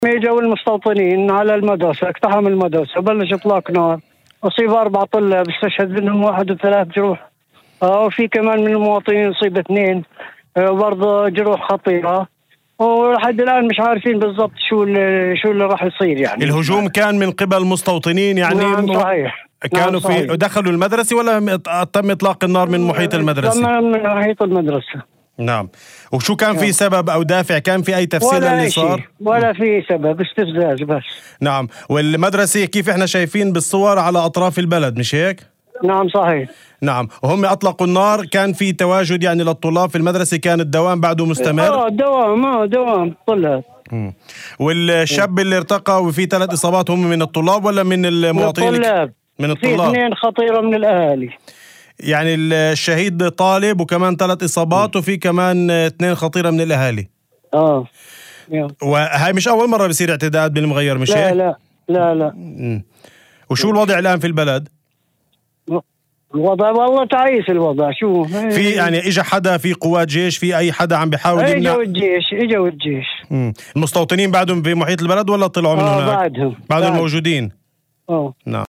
وأضاف في مداخلة هاتفية ضمن برنامج "الظهيرة" ، على إذاعة الشمس، أن الطواقم لا تزال تعمل ميدانيًا، في ظل استمرار إطلاق النار ووجود المستوطنين في محيط القرية.